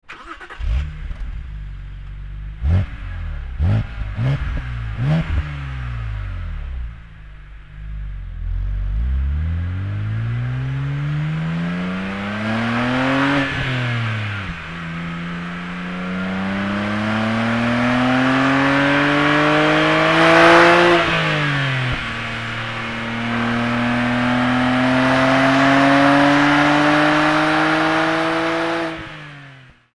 • 宝马的当家跑车，年轻人的梦想，虽只是3.0升发动机，但其能量好像源源不断，呼喊也更有力。